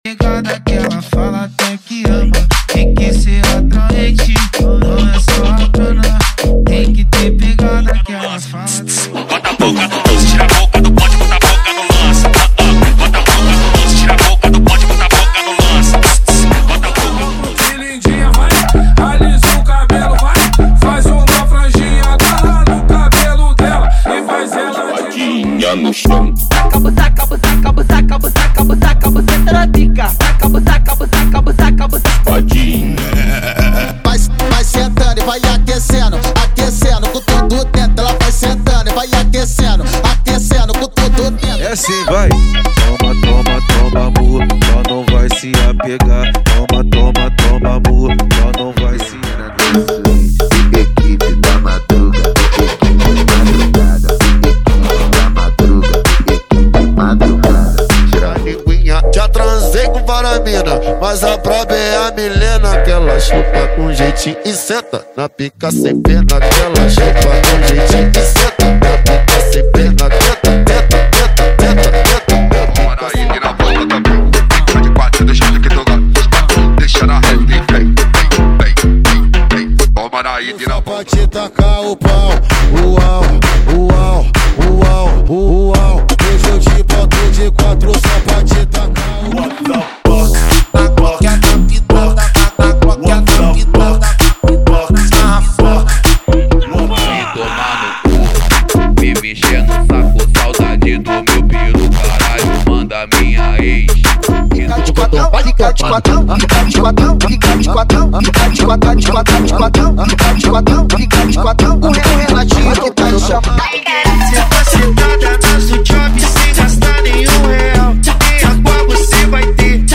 Versão Proibidão
✔ Músicas sem vinhetas